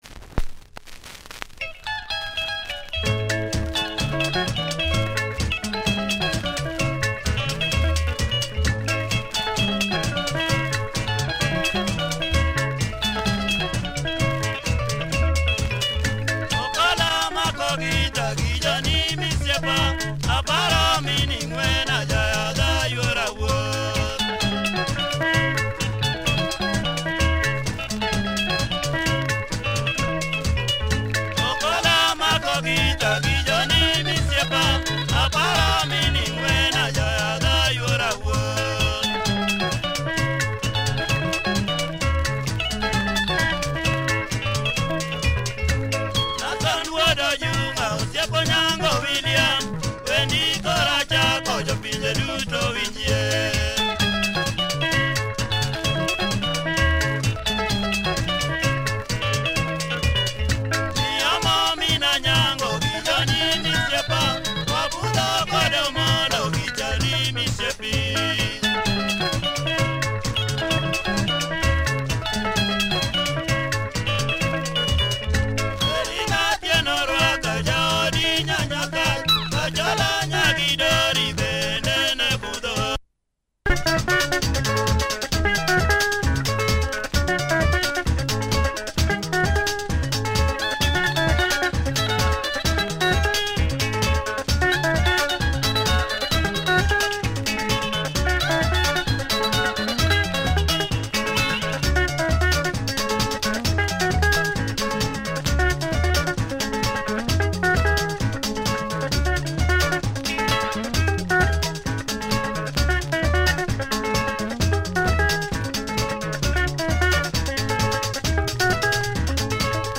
Nice Luo benga, check audio of both sides. https